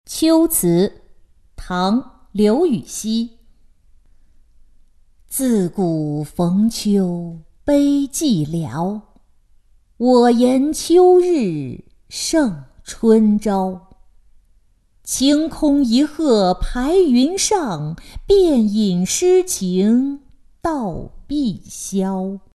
秋词二首·其一-音频朗读